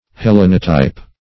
Hellenotype \Hel*len"o*type\, n.